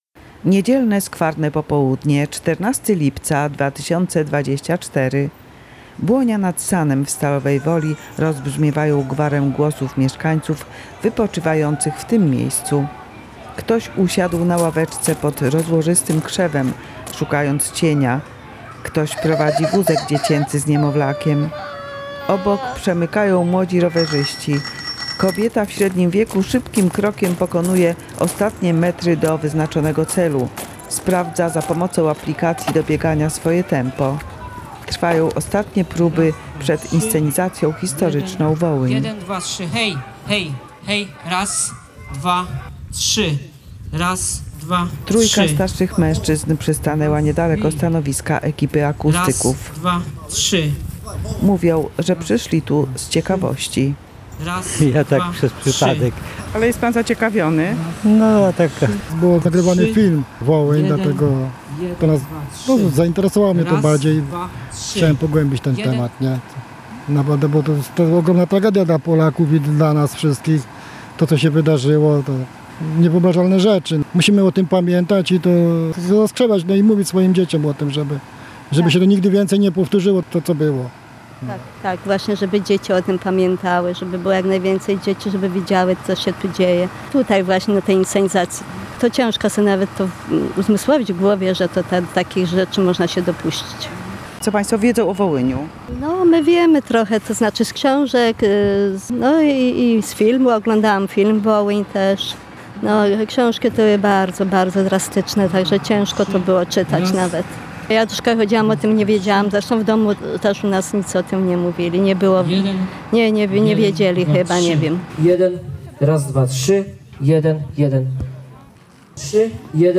Reportaż powstał na kanwie inscenizacji historycznej zorganizowanej 14 lipca 2024 roku w Stalowej Woli.